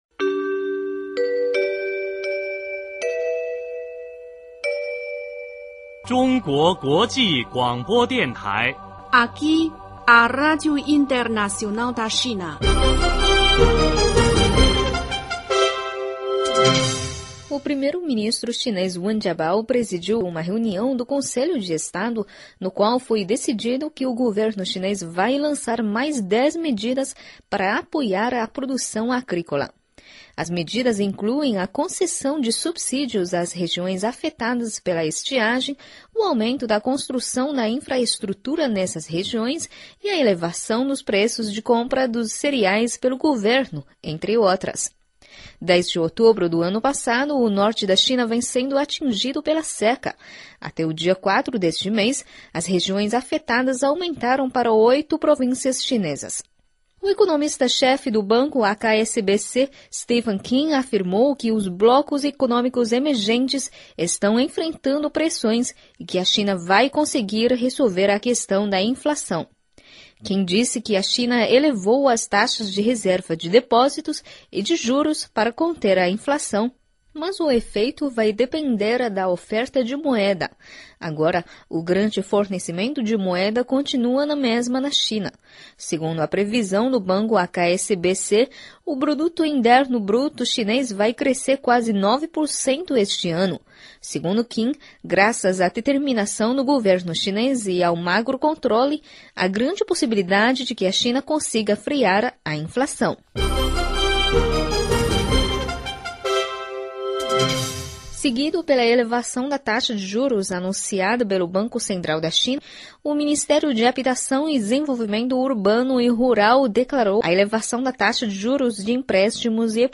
Boletim Rádio China.